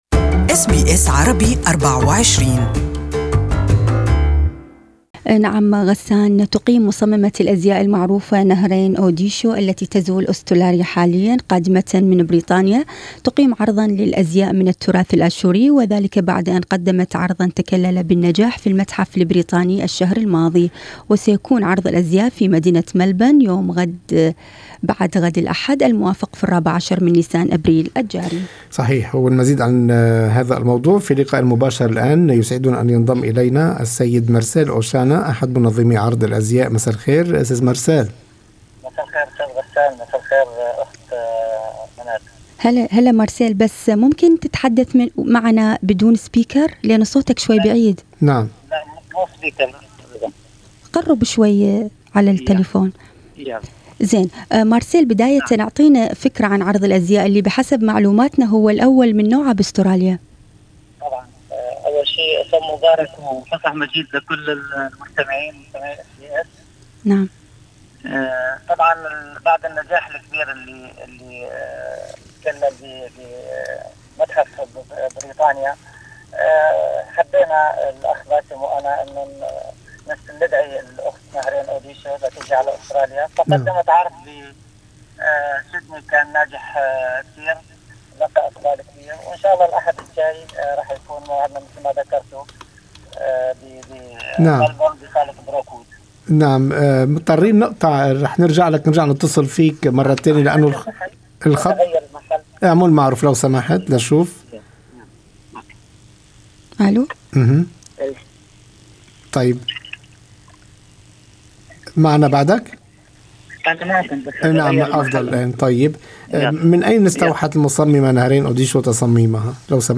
لقاءِ مباشر